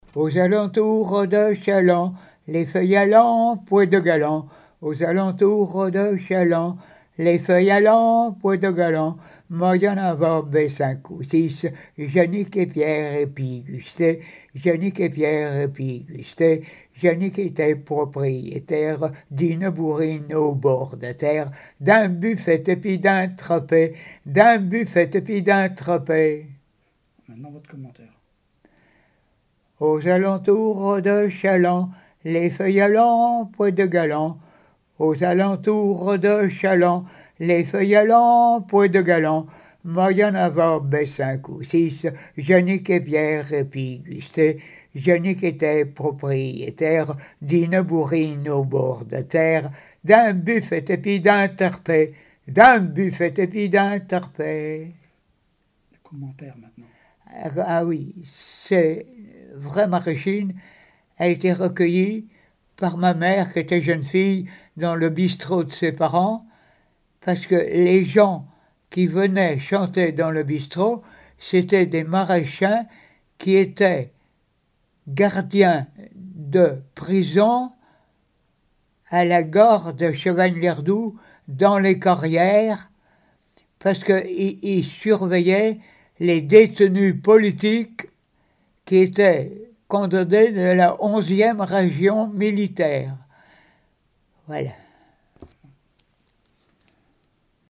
danse : branle : courante, maraîchine
chansons traditionnelles et d'école
Pièce musicale inédite